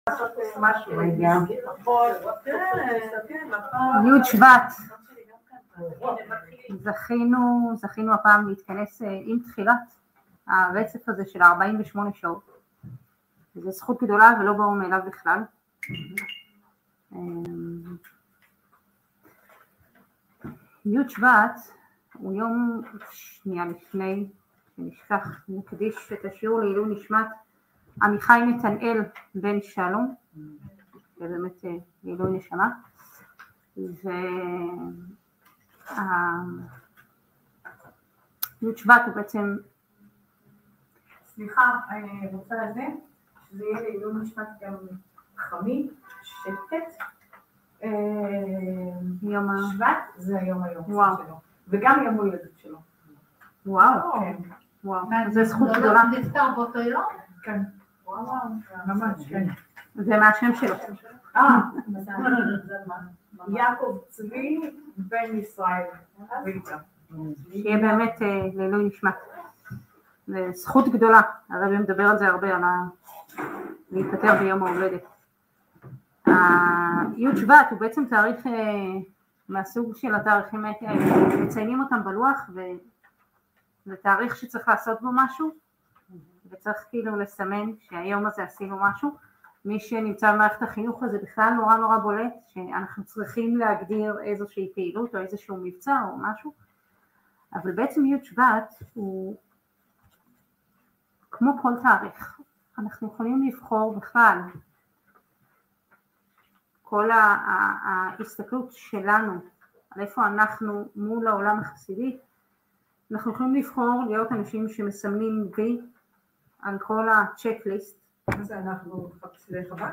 התוועדות י' שבט תשפב